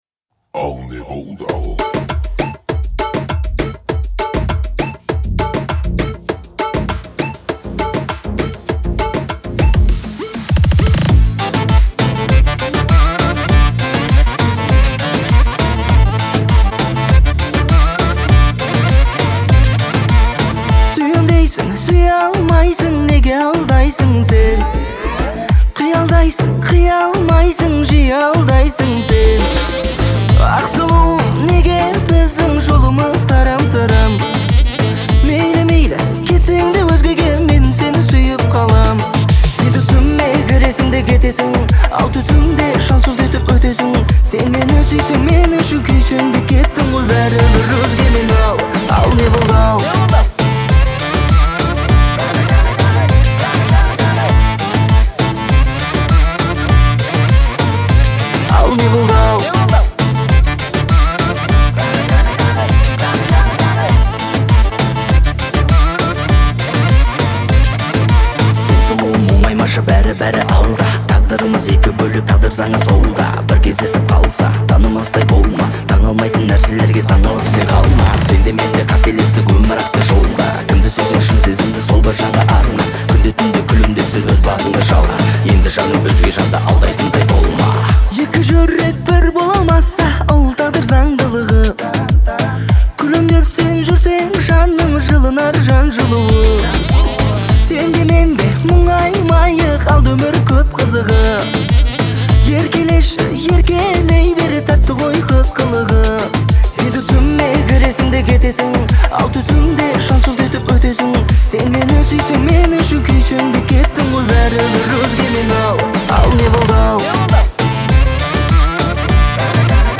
это яркий пример казахской поп-музыки
своим чувственным вокалом и искренностью исполнения